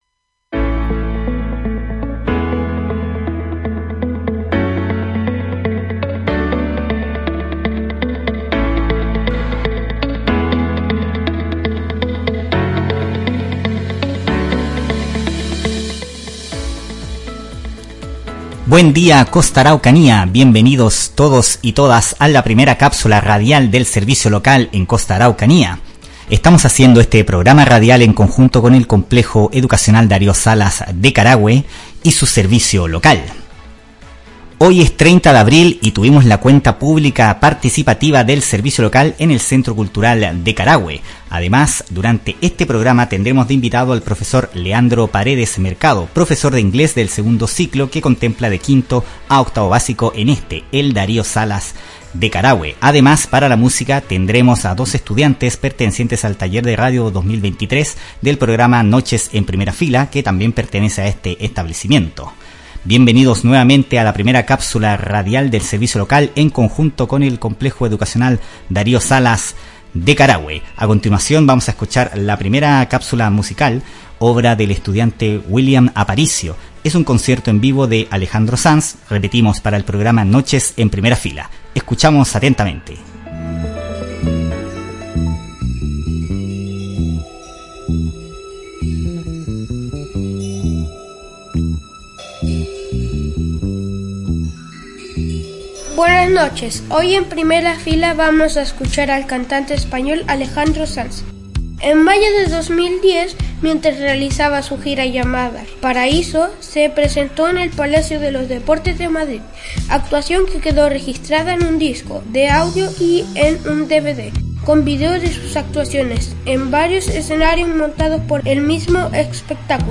El Servicio Local de Educación Pública Costa Araucanía (SLEPCA) y el Complejo Educacional Darío Salas Díaz de Carahue, se unieron para realizar Cápsulas Radiales en el Locutorio del establecimiento educacional.